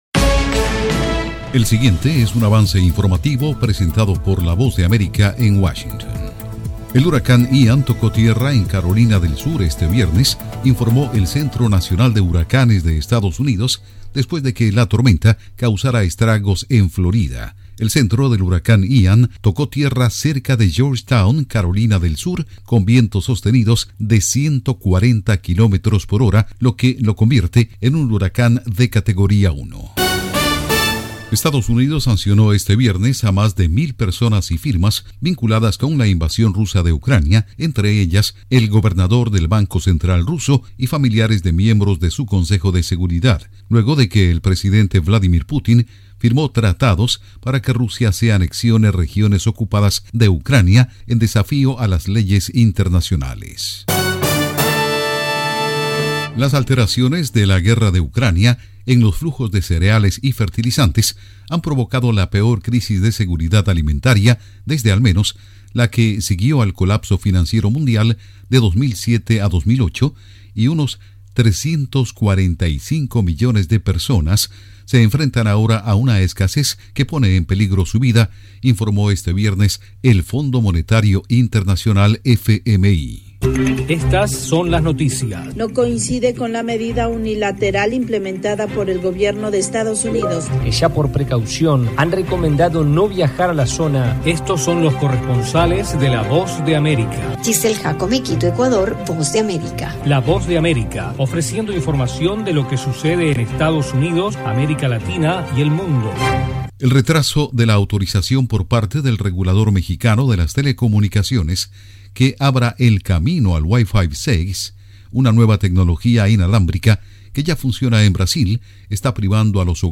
Avance Informativo 4:00 PM
El siguiente es un avance informativo presentado por la Voz de América en Washington.